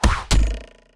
carnival knife thrower.ogg